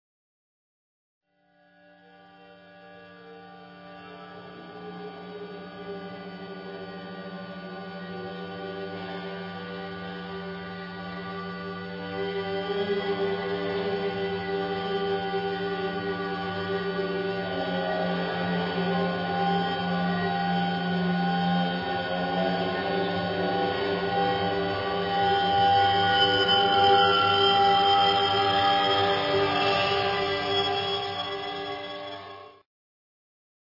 これぞドローン系トリップ音楽の極地！！